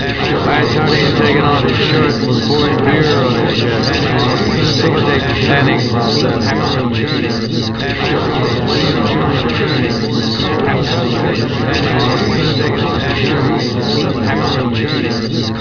In the first, short speech recordings are obscured by a noise which is meant to resemble that in a crowded room with several people talking at the same time.
• In all examples, the speech consists of (slightly slurred) American English, spoken by a man
• All sound files are in the  .wav format (mono)
Part 1: Obscured speech